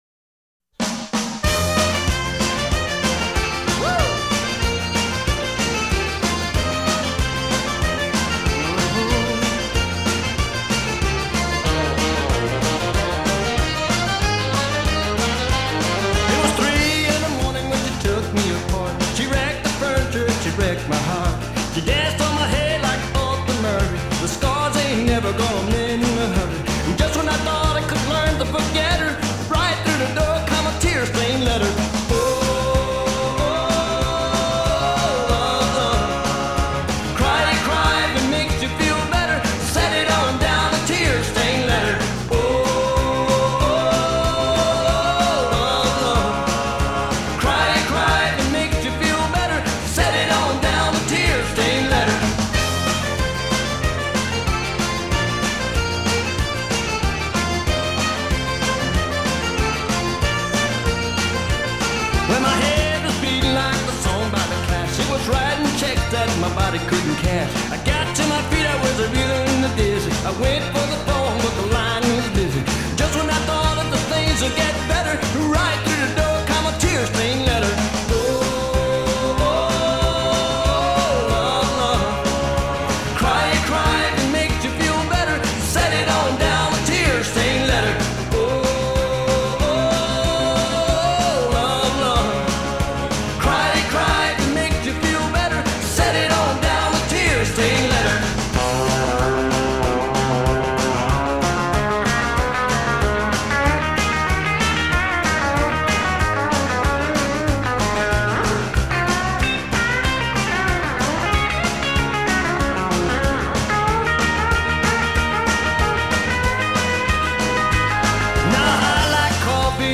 Louisiana Cajun singer/accordionist
raucous energy